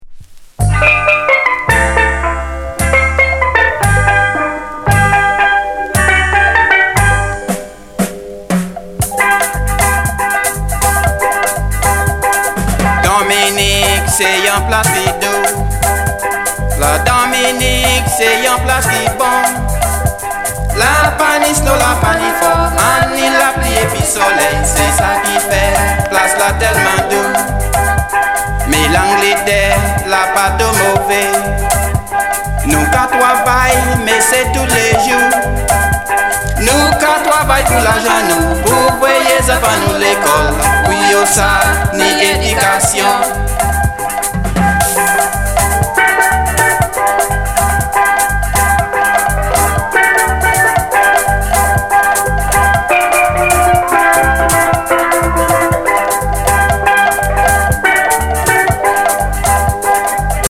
Genre: Calypso